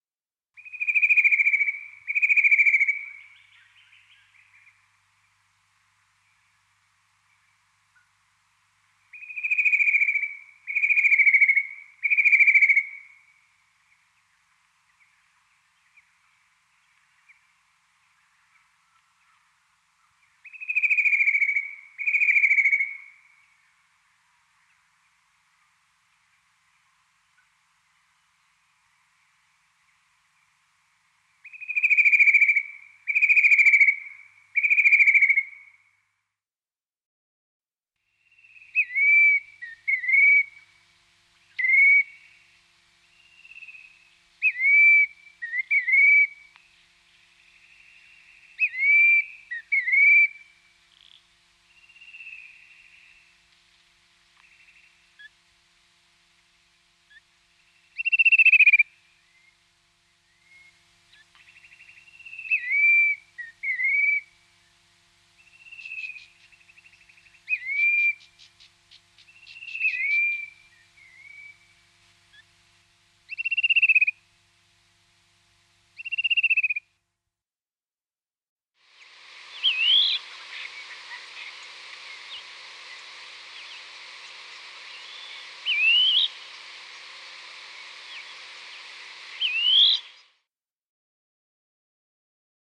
Songs & Calls
fan-tailed-cuckoo-web.mp3